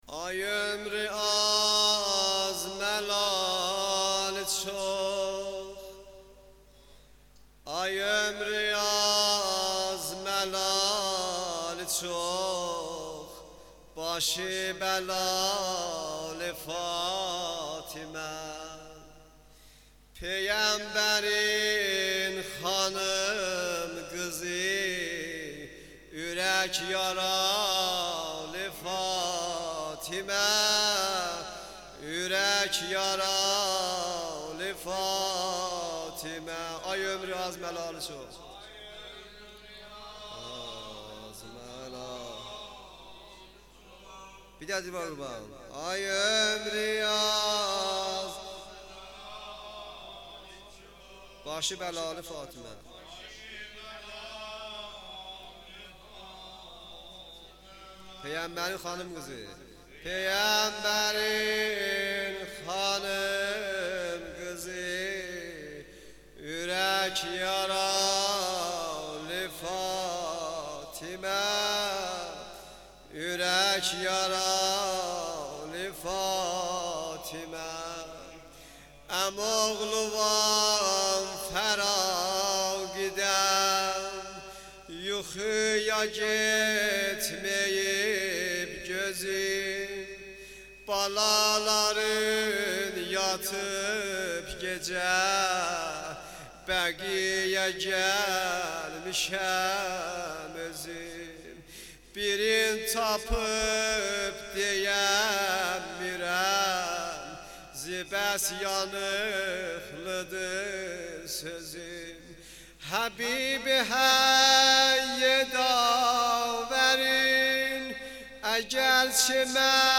مداحی
جلسه هفتگی